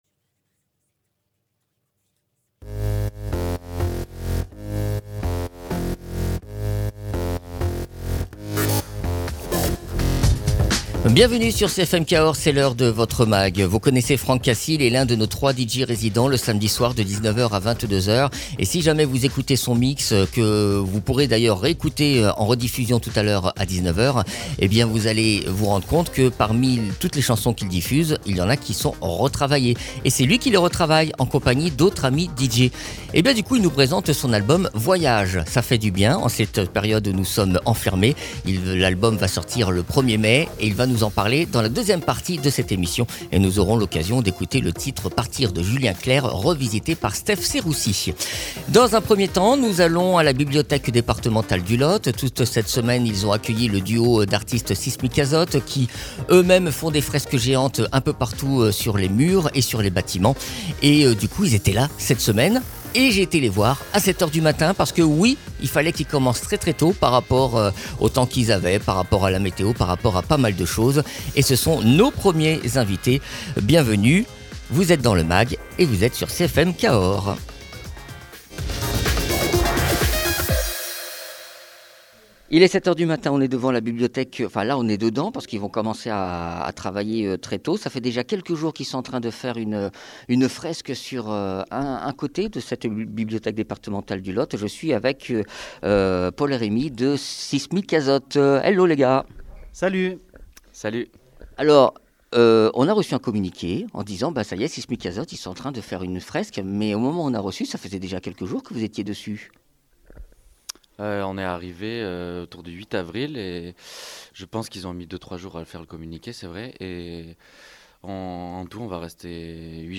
Invité(s) : Simikazot, artistes plasticiens.